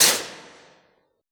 Index of /90_sSampleCDs/Best Service - Extended Classical Choir/Partition I/CONSONANTS